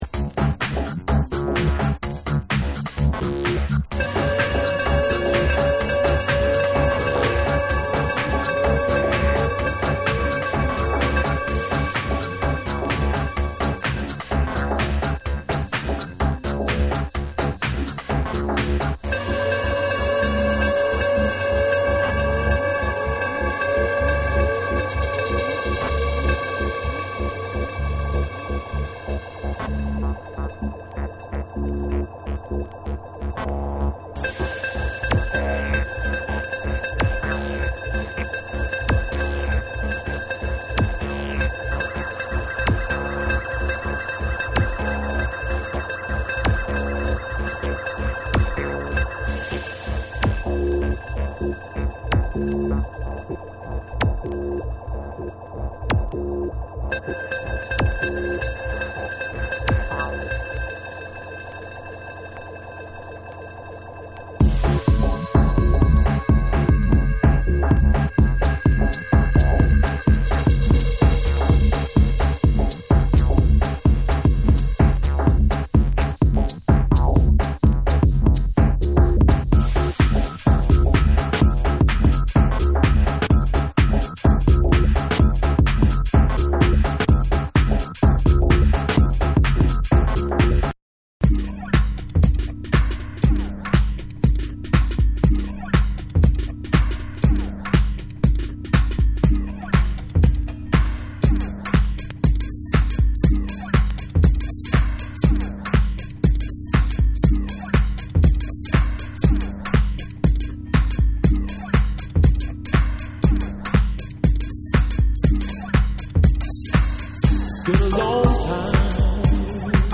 ※薄いスリキズありますが然程音には影響ありません。
TECHNO & HOUSE